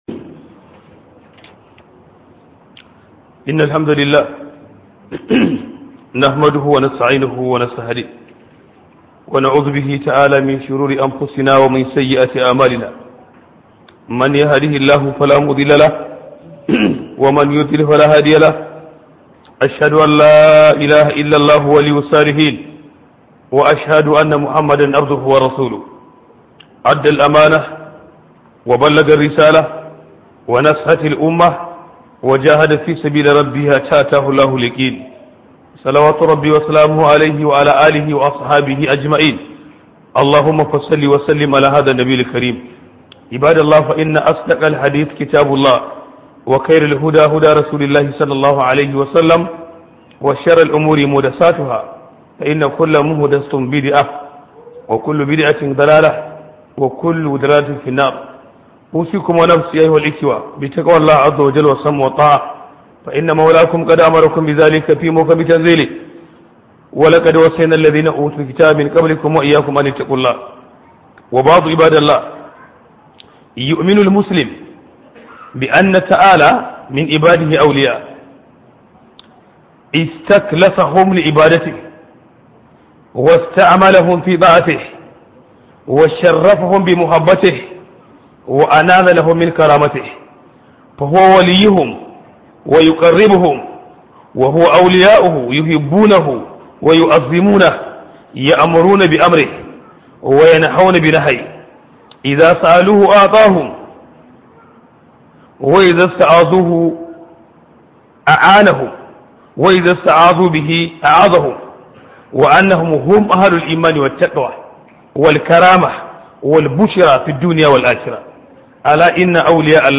070 Huduba Mai Taken Wanda Allah Yazabesu